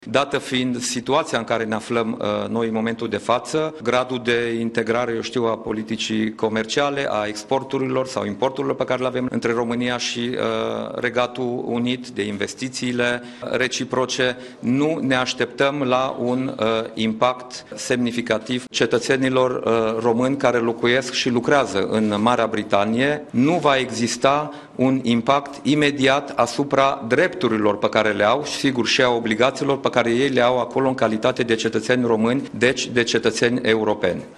Premierul Dacian Cioloş a declarat, într-o conferinţă de presă la Palatul Victoria, că ieşirea Marii Britanii din UE nu va avea un impact semnificativ, pe termen scurt, asupra economiei şi că românii care muncesc în Regatul Unit nu vor fi afectaţi în drepturile pe care le au în calitate de cetăţeni europeni.